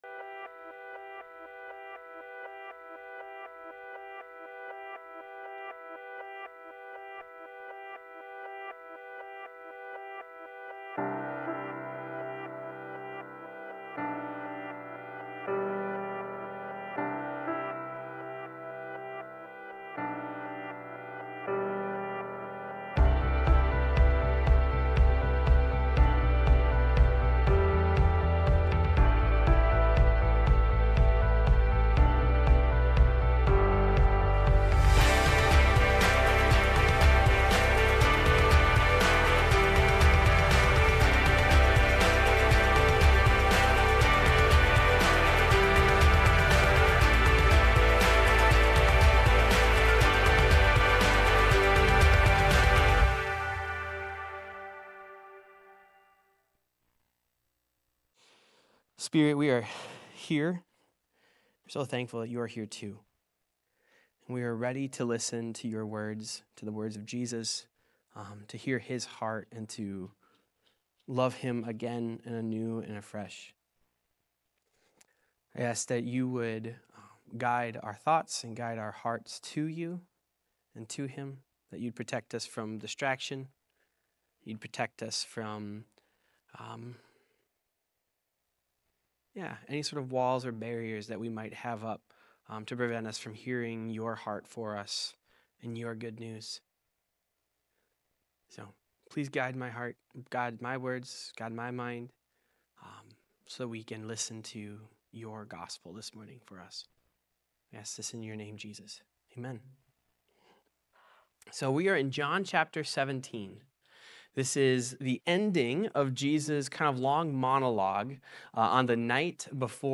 Jesus’ Prayer Sermon- February 8, 2026
Jesus-Prayer-Sermon-2.8.26.m4a